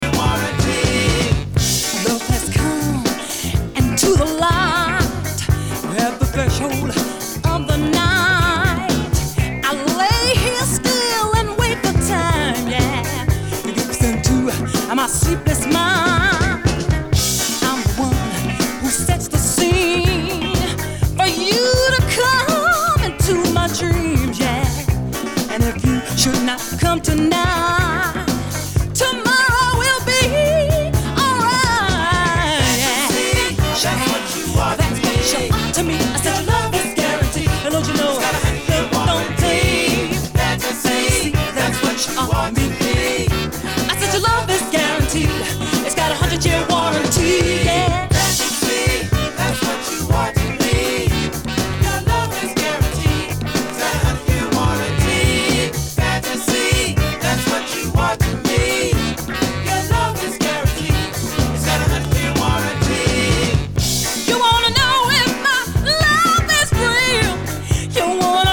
a thrilling female vocalist jazz-funk classic
re-edited